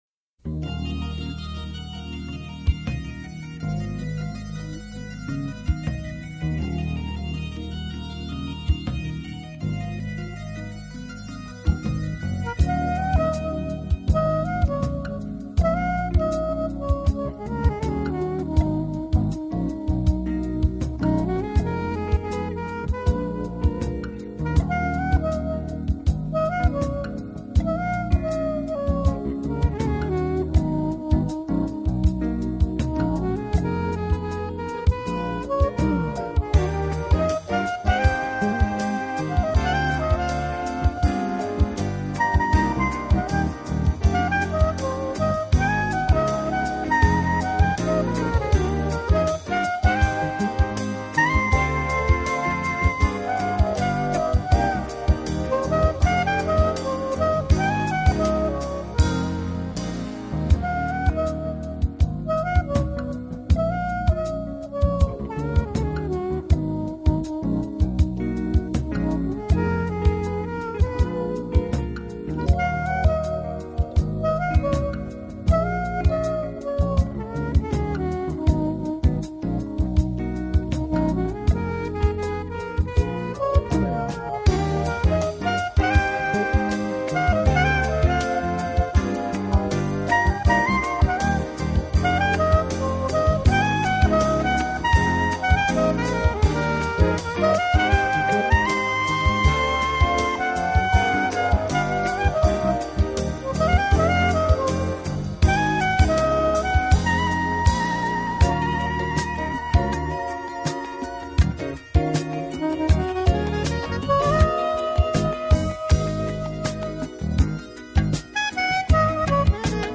他的演奏风格包容了Jazz、R&B、Pop，不